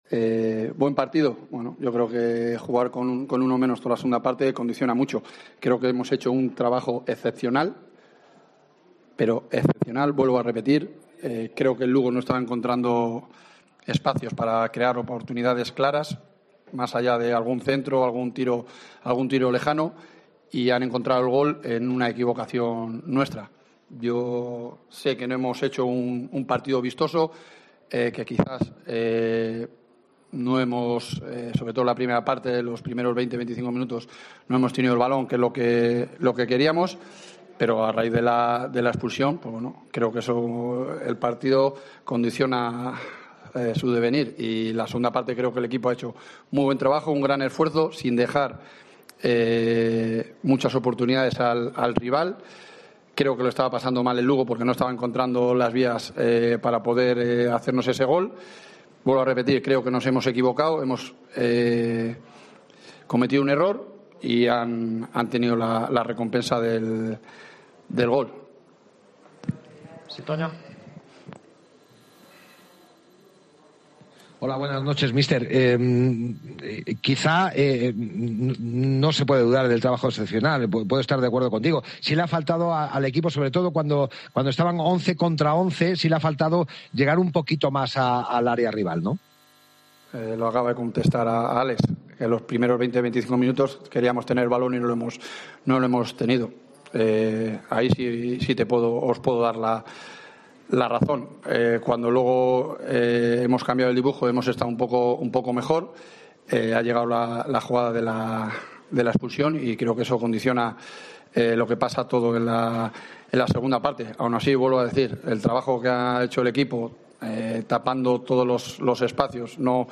AUDIO: Escucha aquí las declaraciones del entrenador de la Deportiva, Jon Pérez Bolo, y del míster del Lugo, Mehdi Nafti